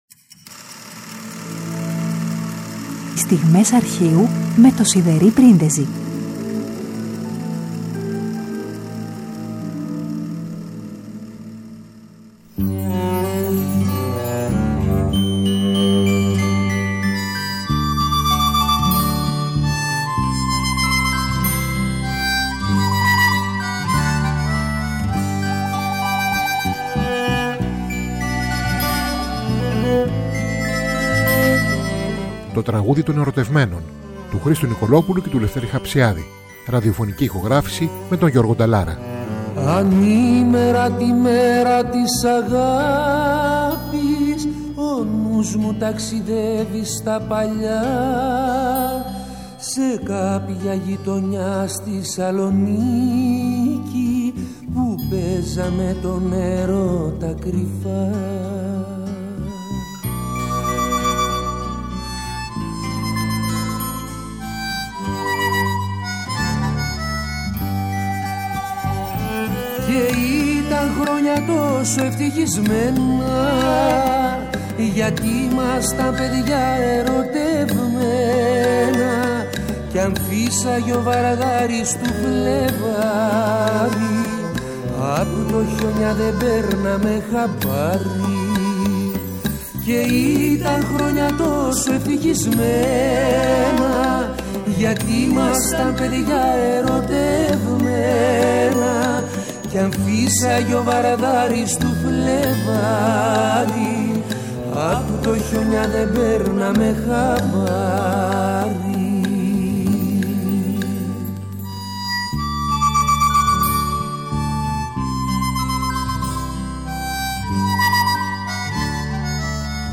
από ραδιοφωνική ηχογράφηση